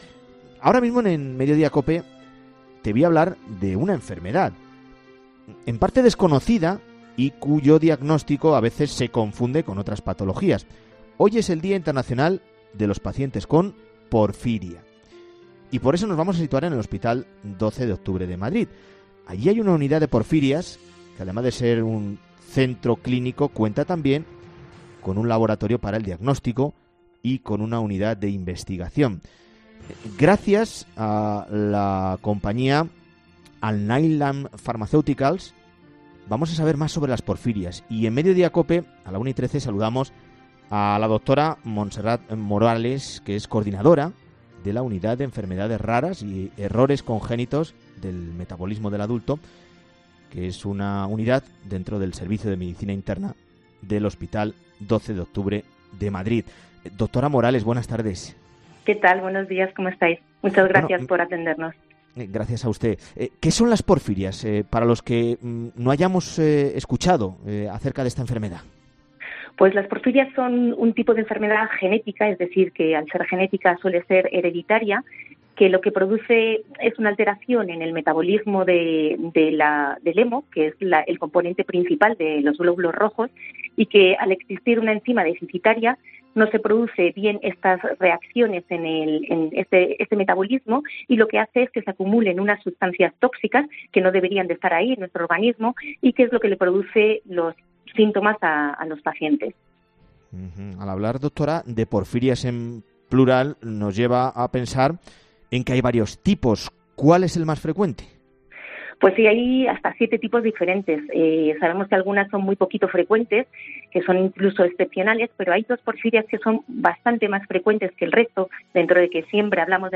Noticia patrocinada por Alnylam Pharmaceuticals